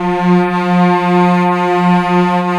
Index of /90_sSampleCDs/Roland L-CD702/VOL-1/CMB_Combos 1/CMB_Cello Orch
STR ARCOCL02.wav